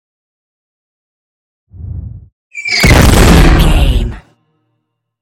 Scifi whoosh to hit 426
Sound Effects
Atonal
dark
futuristic
intense
tension
woosh to hit